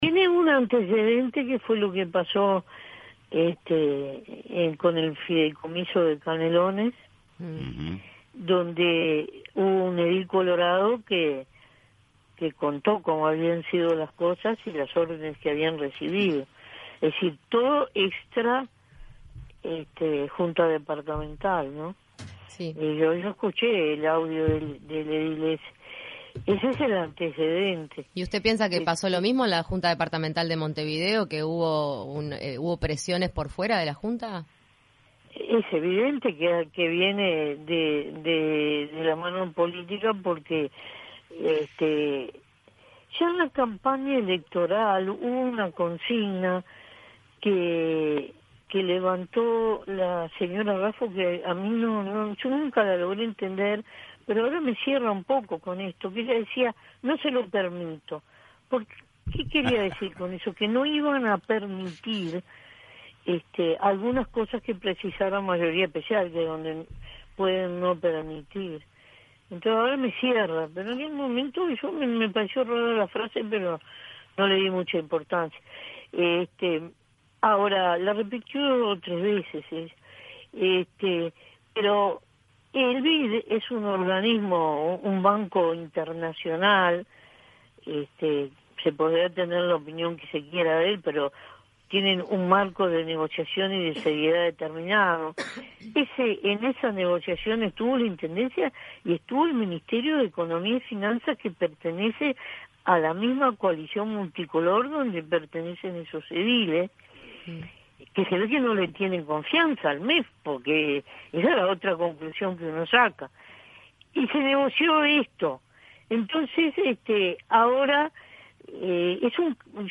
En entrevista en Punto de Encuentro la senadora Lucia Topolansky hizo referencia al tema: “Esto ya viene por el cruce del fideicomiso en Canelones.
Escuche la entrevista completa a Lucia Topolansky: